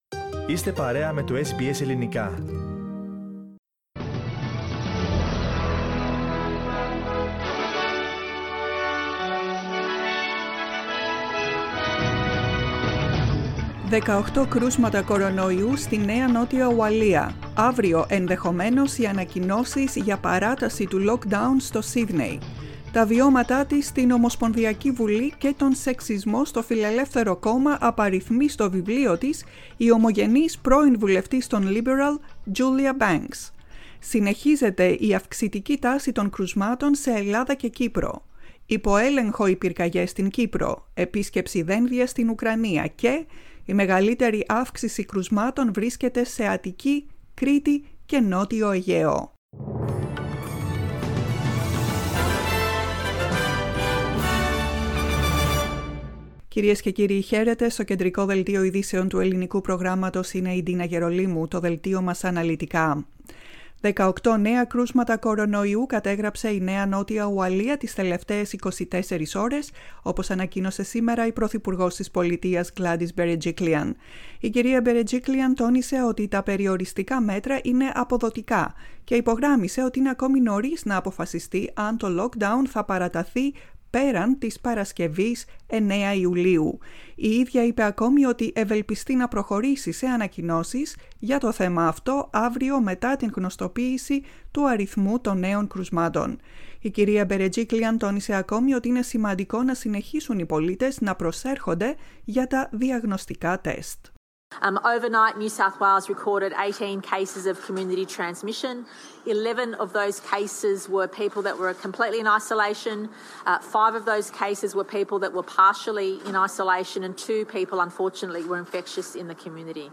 News bulletin in Greek, 06.07.21